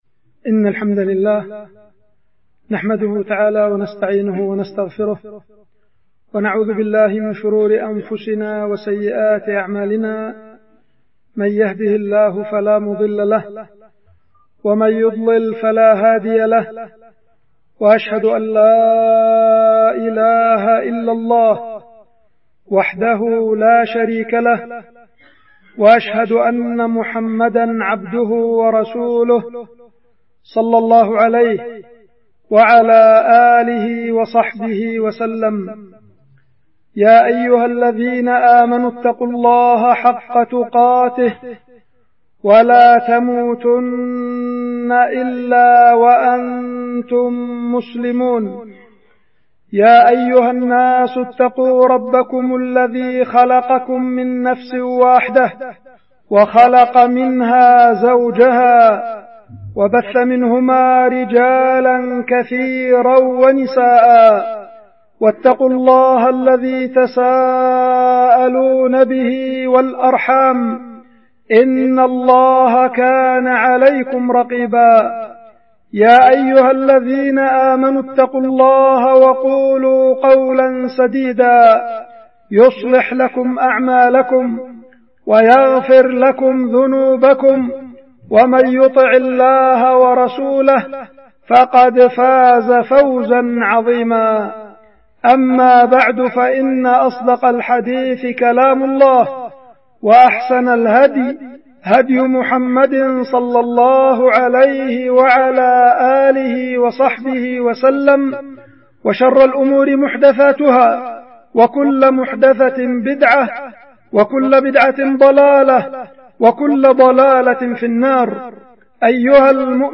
خطبة
القيت في الجامع الكبير في العزلة- مديرية بعدان- إب-اليمن